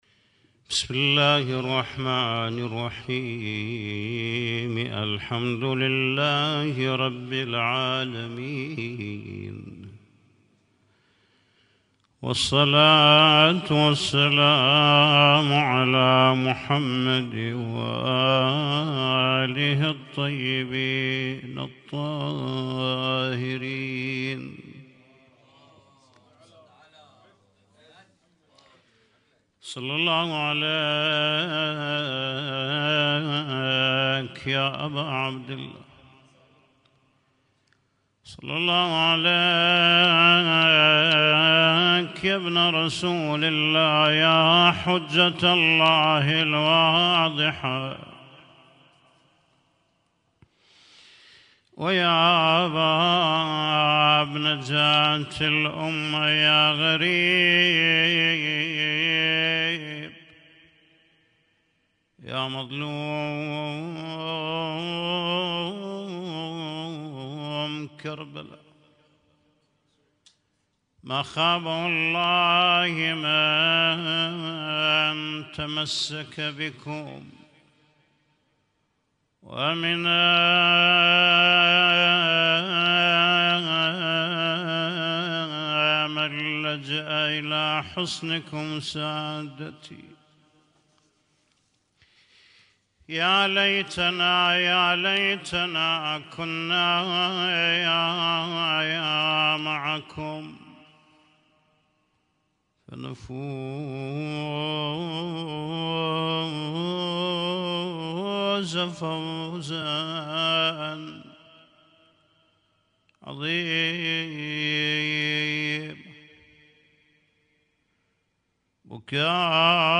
Husainyt Alnoor Rumaithiya Kuwait